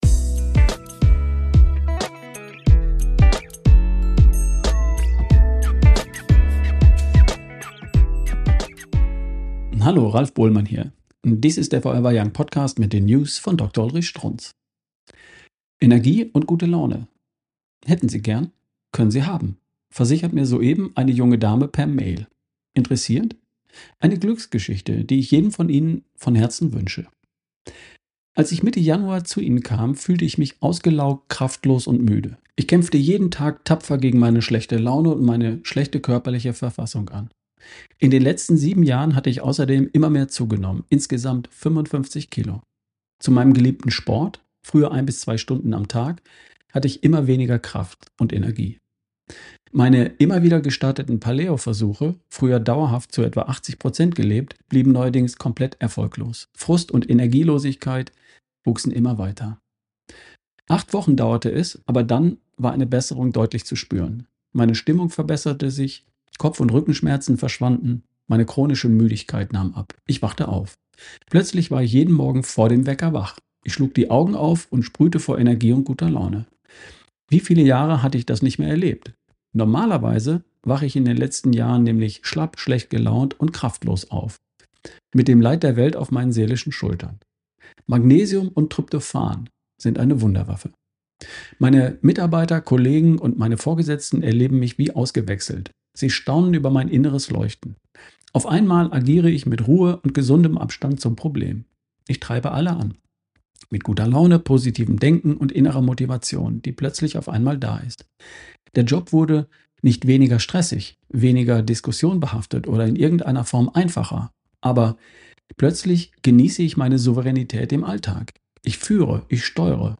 Ausgewählte News gibt es hier im Podcast nun auch zum Hören.